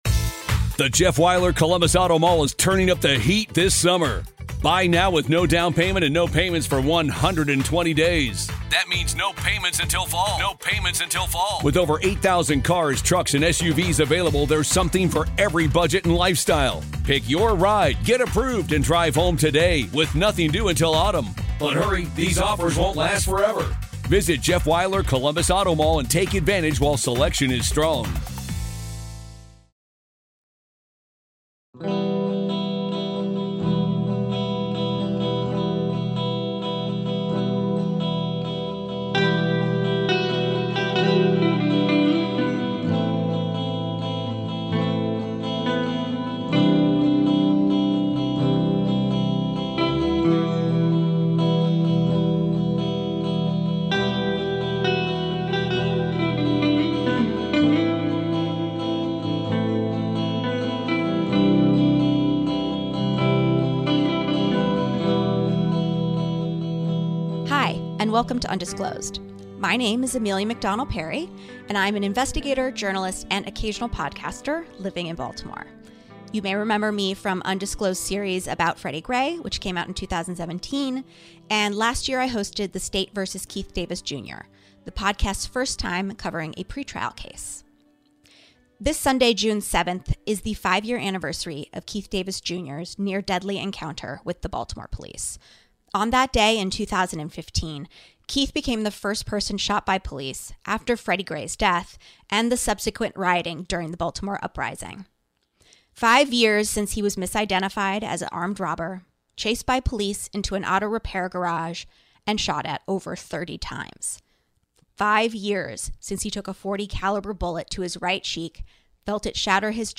Episode scoring music